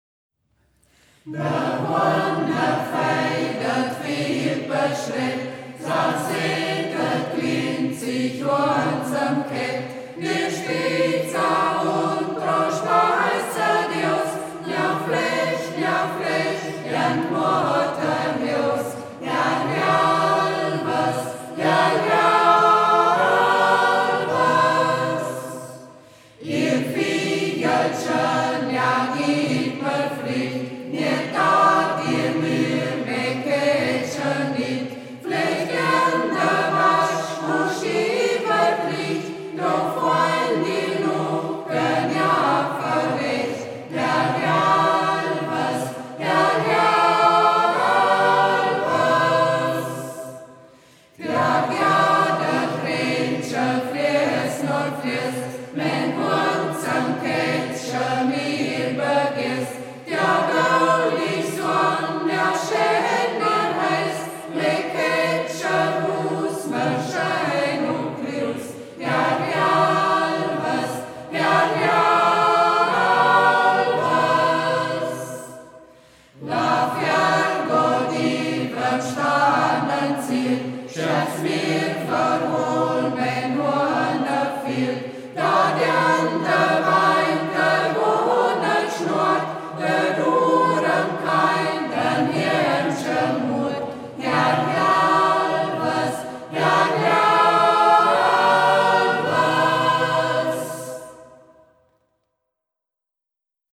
Ortsmundart: Braller